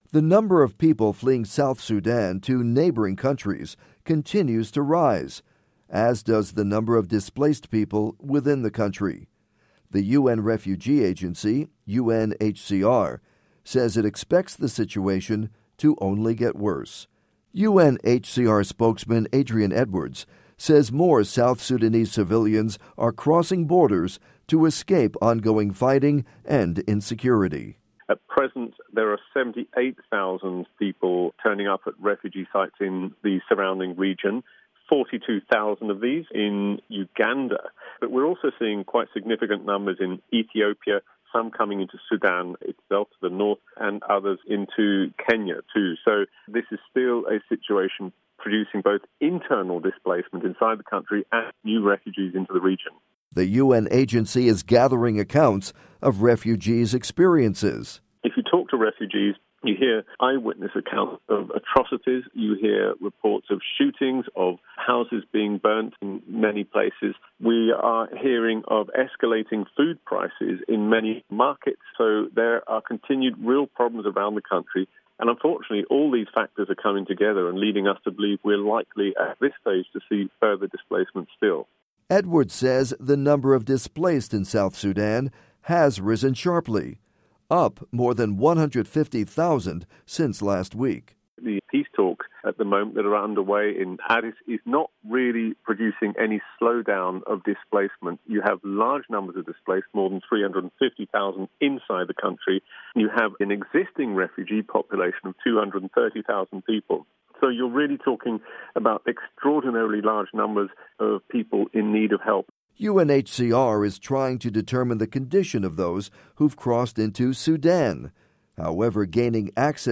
report on South Sudan refugees and displaced